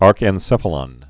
(ärkĕn-sĕfə-lŏn)